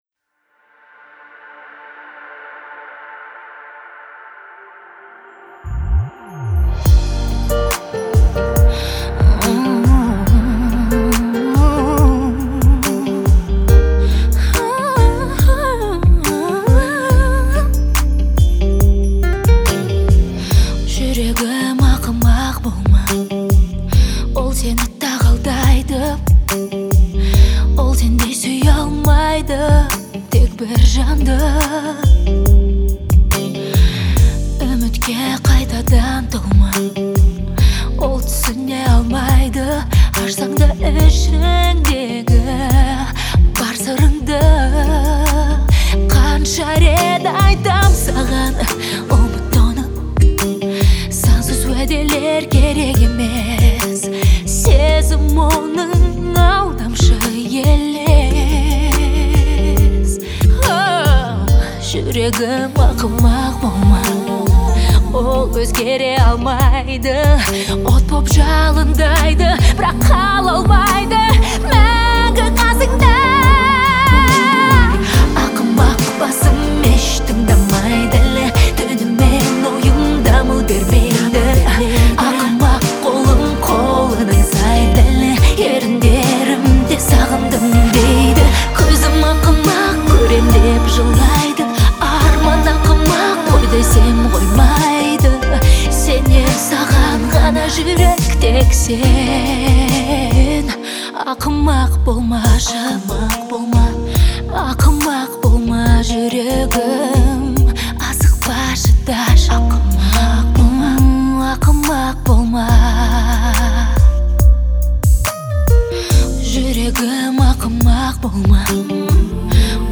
относится к жанру поп
отличается ярким и эмоциональным звучанием